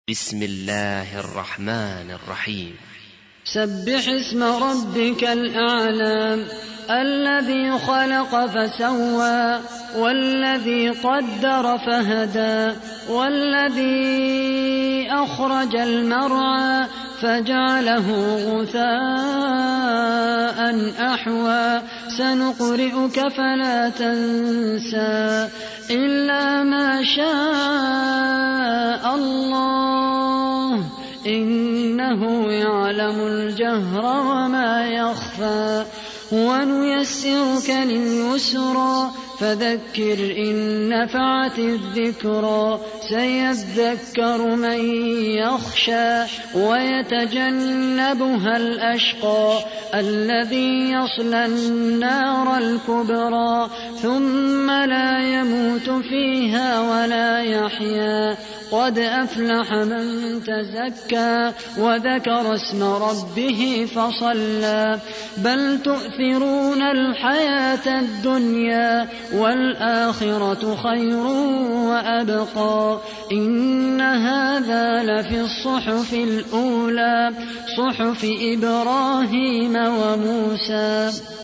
سورة الأعلى / القارئ خالد القحطاني / القرآن الكريم / موقع يا حسين